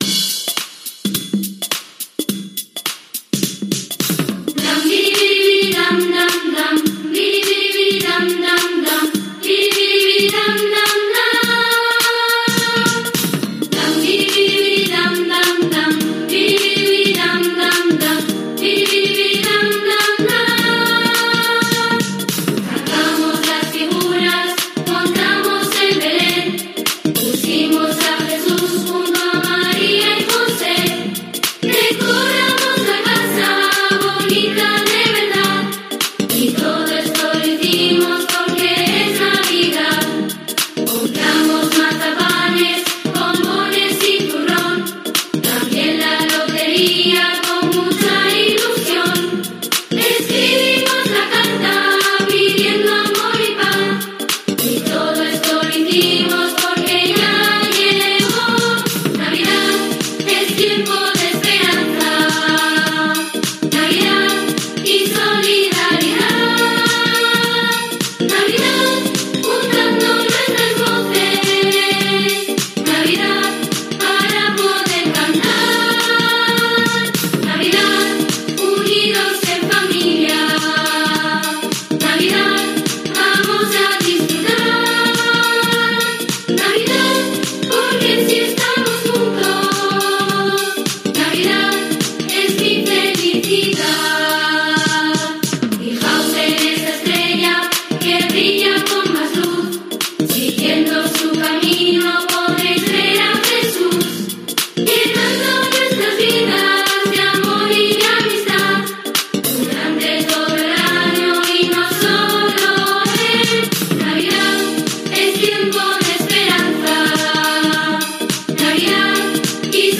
"Es la Navidad": el villancico del Coro Cuchuflete de Arnedo que resuena por todo el mundo desde La Rioja
Con "Es la Navidad" conocemos al Coro Cuchuflete de Arnedo (La Rioja), un grupo de 35 niños y niñas de entre 7 y 15 años pertenecientes al CEIP Antonio Delgado Calvete de Arnedo.
Según lo definen ellos mismos: "es un villancico con un ritmo muy moderno que habla de cómo vivimos hoy en día la Navidad, desde que montamos el belén y compramos los turrones o la lotería, hasta todo aquello que nos une alrededor de esta fiesta: la familia, cantar juntos, la esperanza de un nuevo año mejor, las creencias religiosas..."